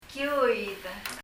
kiuid [kiuið] ムクドリの類の鳥、だそうで、参考サイトは、 kiuid : Micronesian_Starling (Wikipedia) kiuid : IBC(International Bird Collection) （後者は鳴き声を聞くことができますが、パラオのインターネット環境では ダウンロードにひと苦労です。